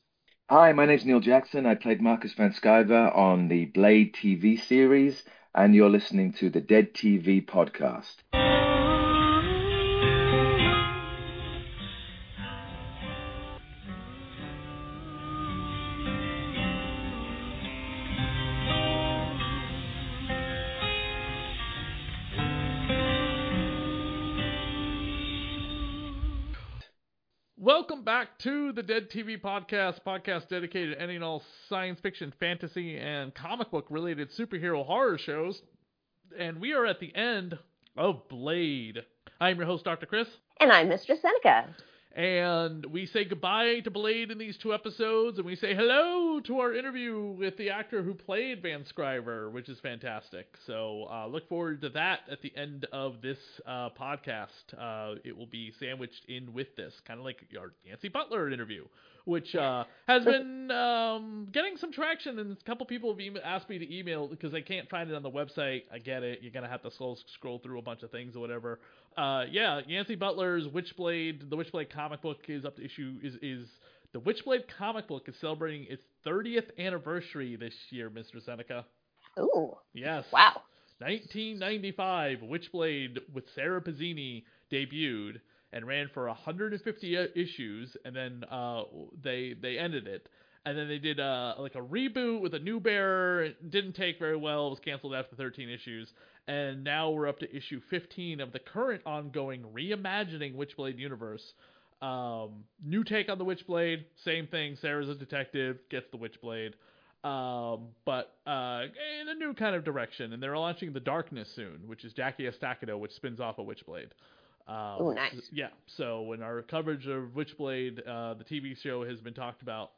Actor Neil Jackson Interview from the Marvel Comics tv series Blade here on the Dead Tv Podcast finale coverage
Hopefully you enjoy this interview with him at the end of the podcast.